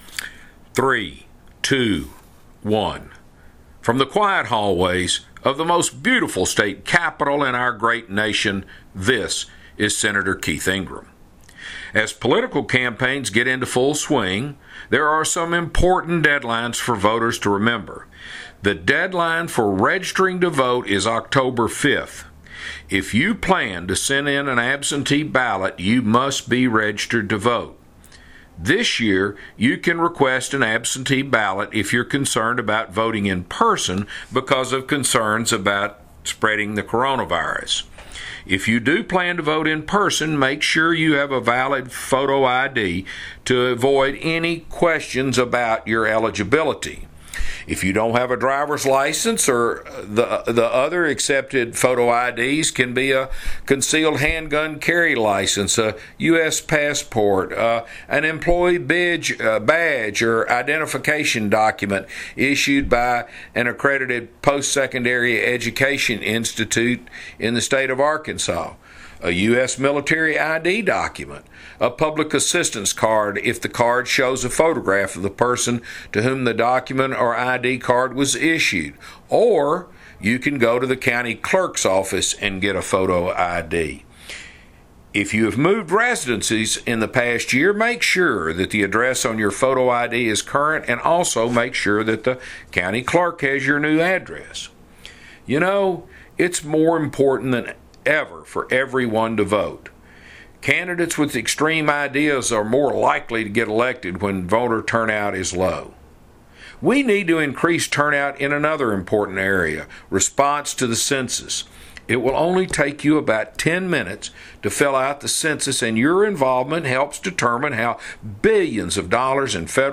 Weekly Address – September 11, 2020 | 2020-09-10T22:26:37.589Z | Sen.